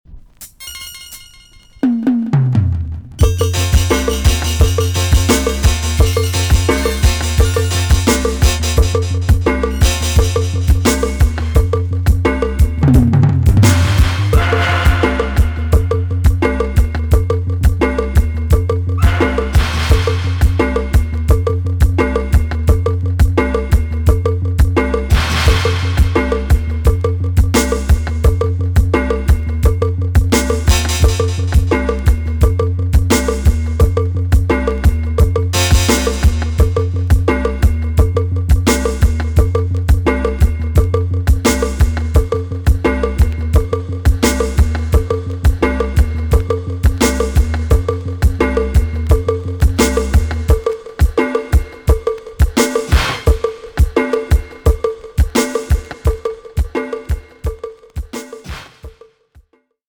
TOP >80'S 90'S DANCEHALL
B.SIDE Version
EX 音はキレイです。